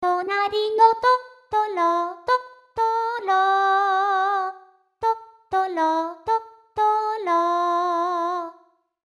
とりあえず即興で短いのを作ってみたんですが、合成音っぽさを完全に脱却させるのは難しそうですね。
「い段」がいかにも合成音っぽい発音になりやすくて、これを誤魔化すのが課題なんでしょうか。